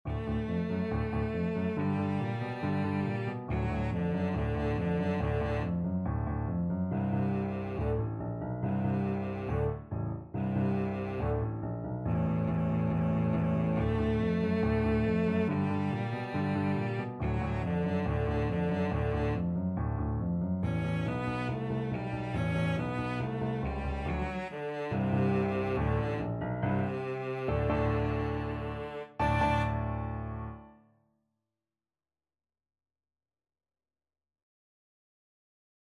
Simple boogie-woogie tune.
=c.140 Presto (View more music marked Presto)
4/4 (View more 4/4 Music)
Arrangement for Cello and Piano
Pop (View more Pop Cello Music)